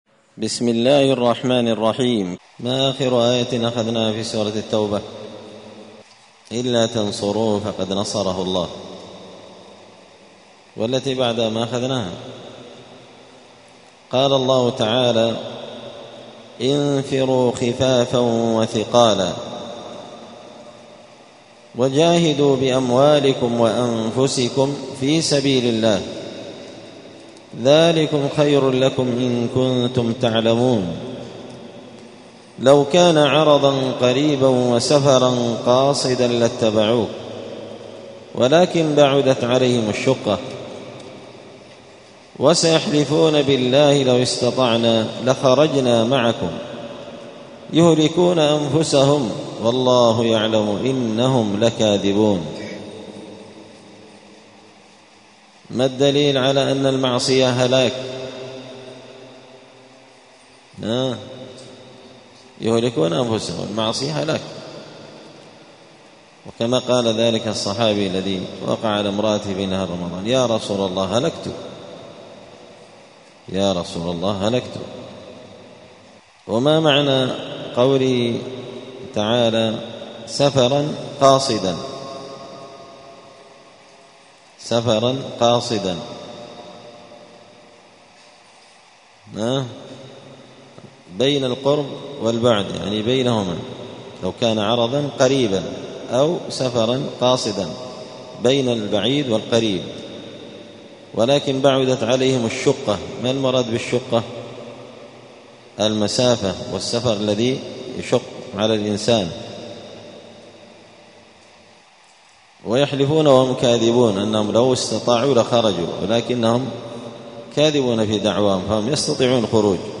📌الدروس اليومية
دار الحديث السلفية بمسجد الفرقان قشن المهرة اليمن